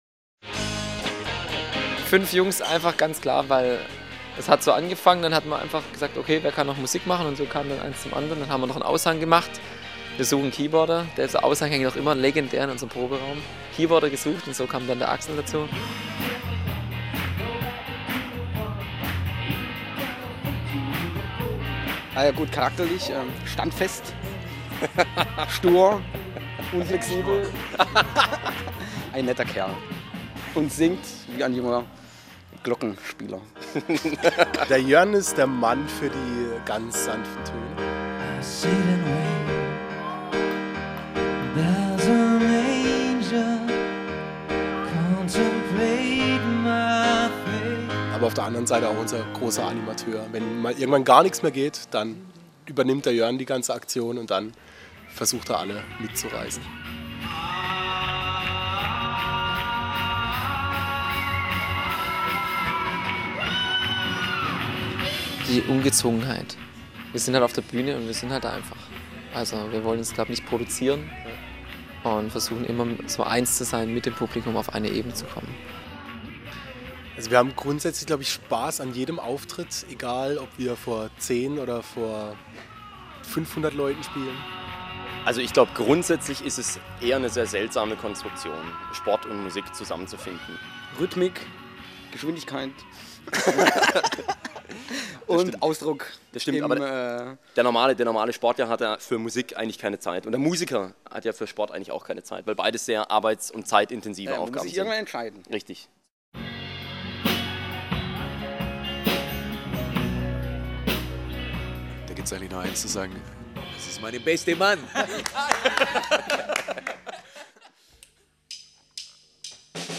Extrahertz stellt vor: Fünf Männer, die alle nicht nur sportlich sind und sich vom Sportstudium kennen, sondern nebenher auch noch in ihrer richtig guten Coverband rocken können.